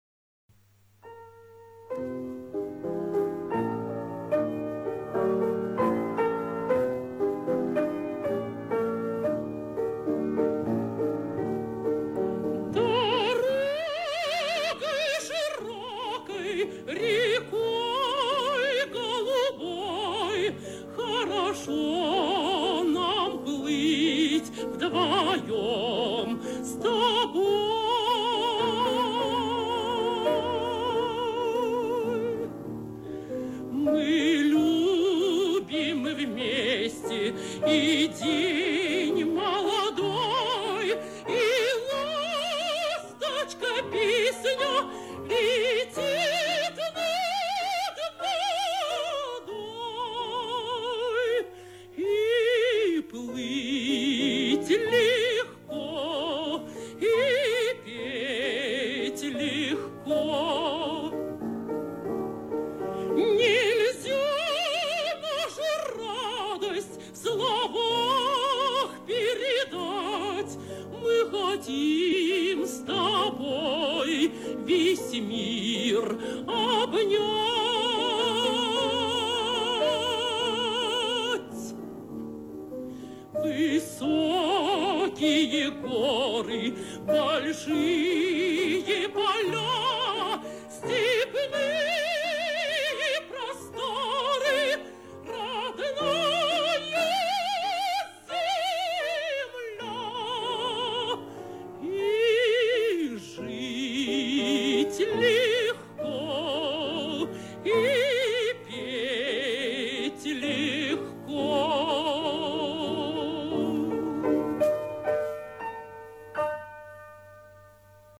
Там с оркестром, а здесь под фортепиано
Источник Радио